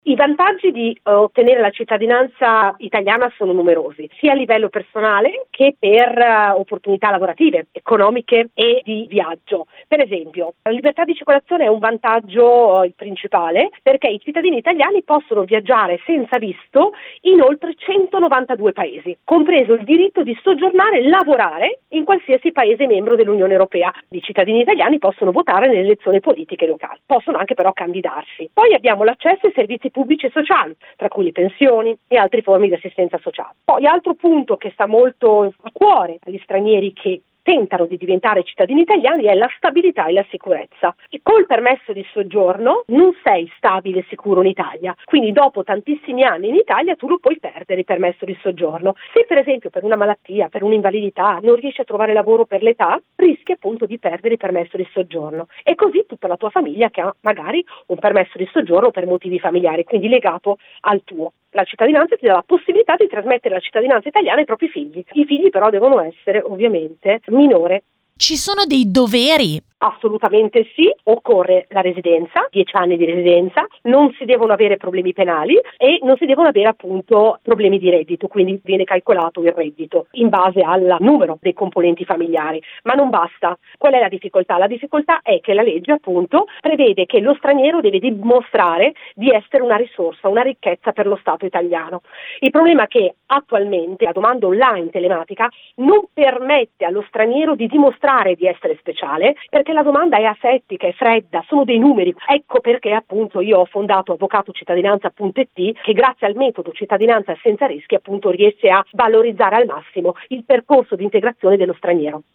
intervistata